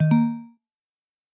Звук на ноутбуке при подключении смартфона